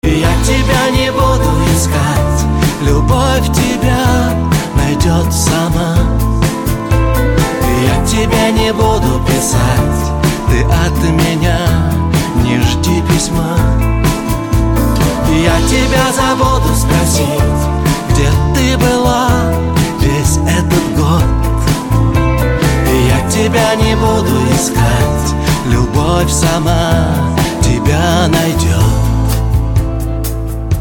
Нарезки шансона